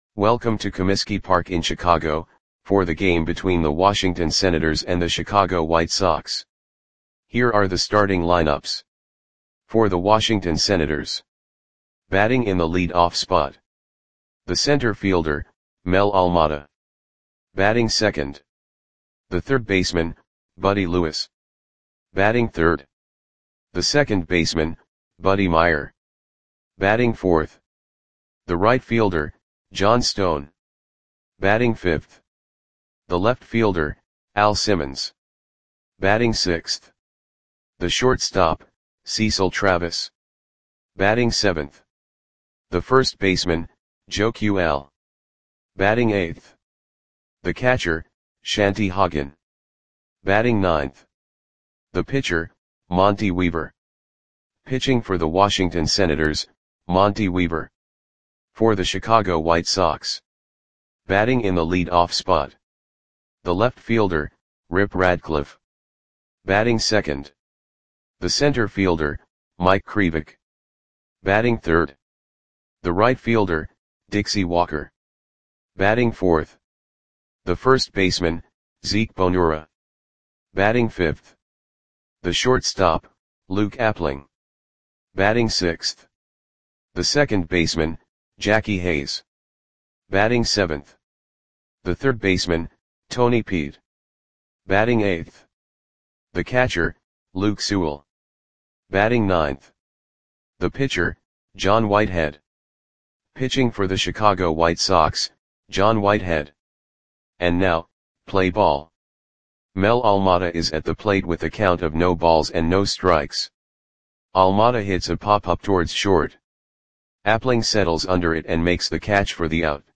Audio Play-by-Play for Chicago White Sox on June 11, 1937
Click the button below to listen to the audio play-by-play.